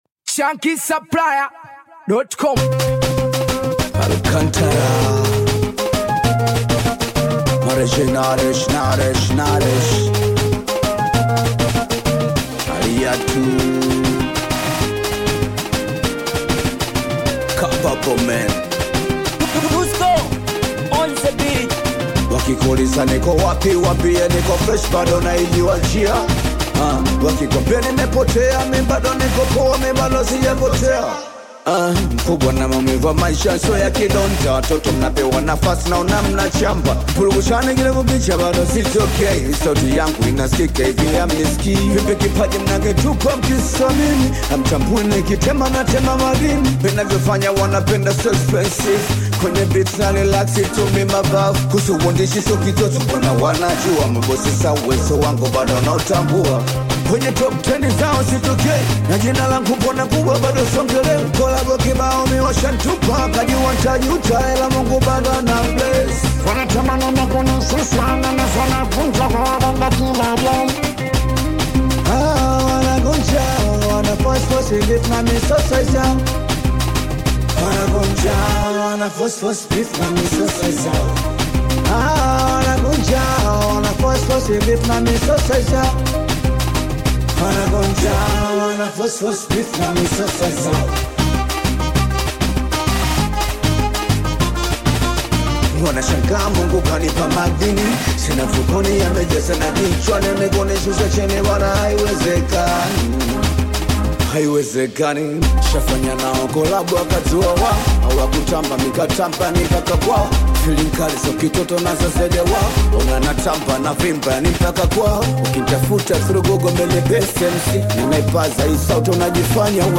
powerful vocals soar over a pulsing, percussive backdrop